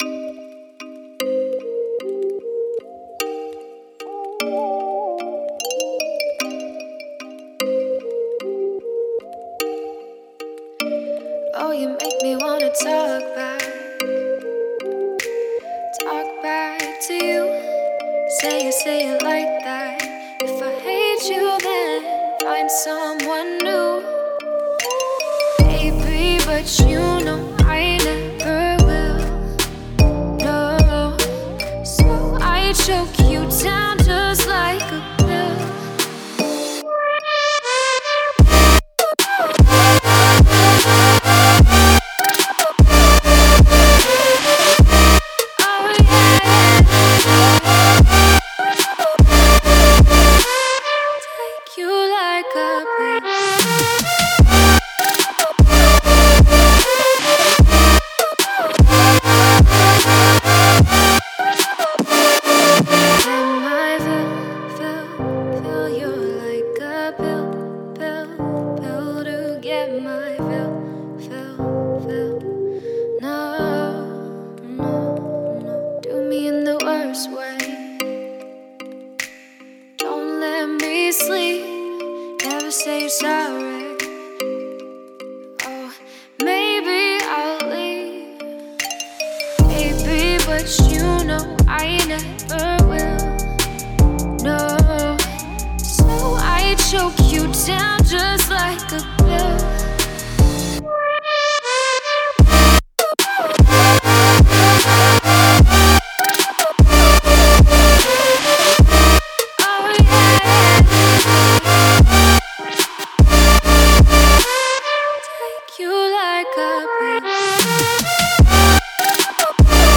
Trap, Quirky, Weird, Epic, Happy